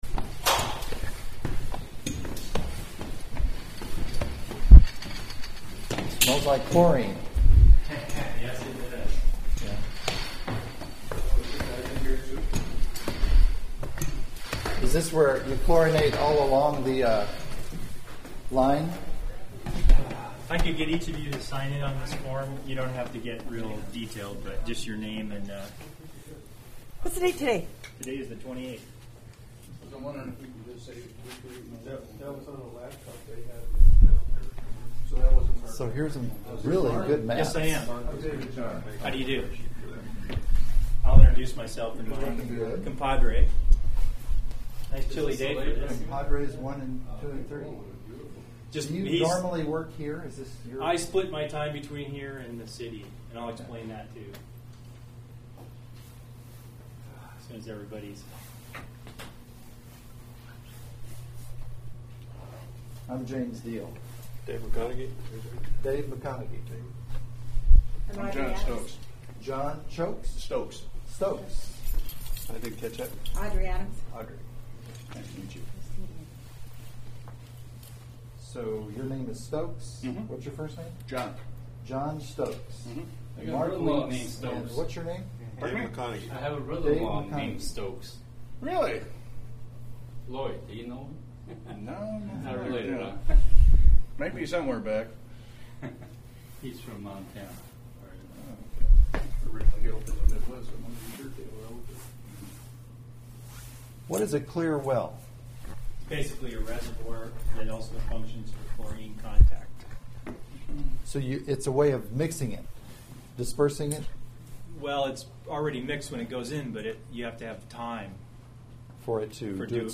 Lake Chaplain tour interview - first tape - 1-28-12